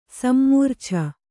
♪ sammūrchana